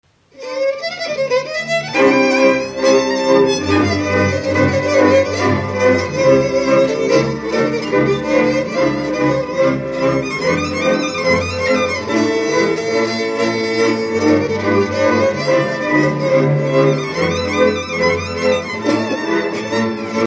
Dallampélda: Hangszeres felvétel
Erdély - Szilágy vm. - Selymesilosva
hegedű
kontra
brácsa
bőgő
Műfaj: Lassú csárdás
Stílus: 3. Pszalmodizáló stílusú dallamok
Kadencia: 8 (b3) b3 1